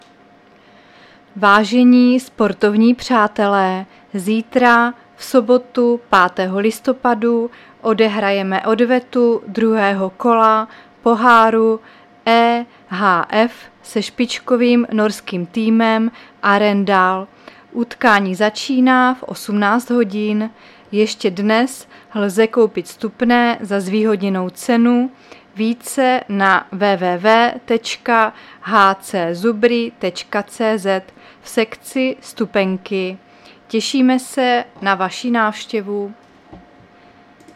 Záznam hlášení místního rozhlasu 4.11.2022